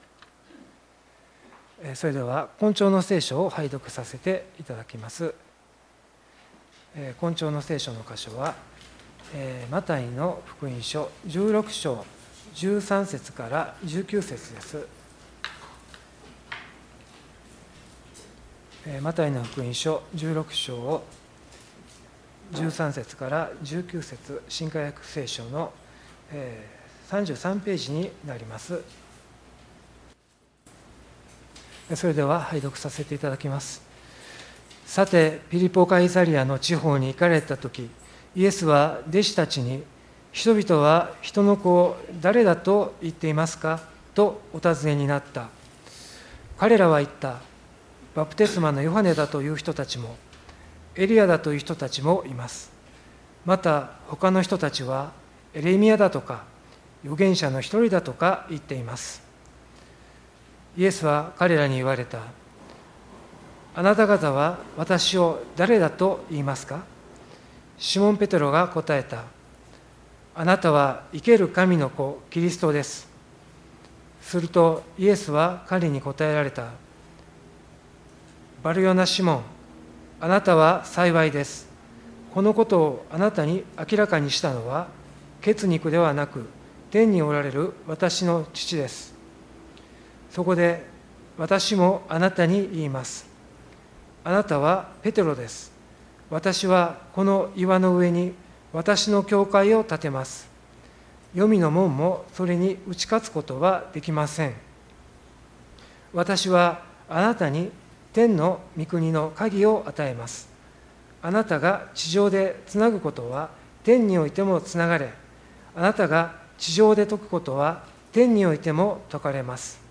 礼拝メッセージ「誰が教会を建てるのか」│日本イエス・キリスト教団 柏 原 教 会